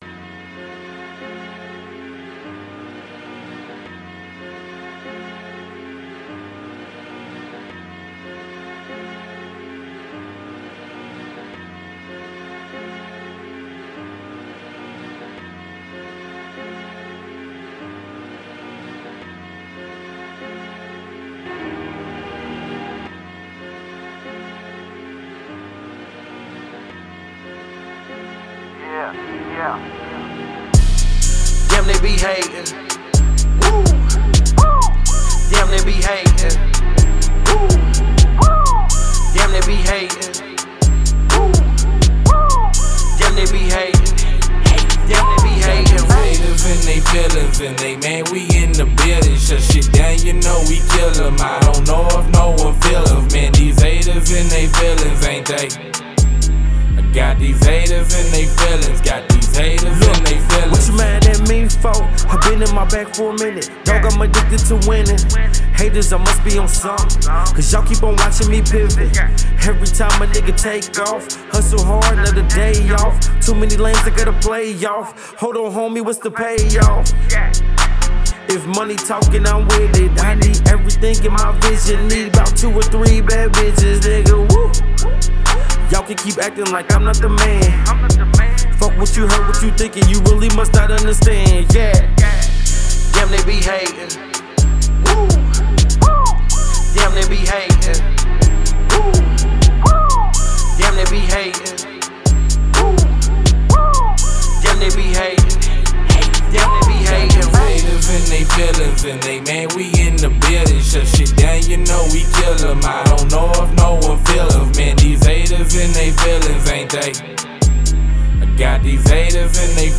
Hiphop
Hip-Hop & R&B group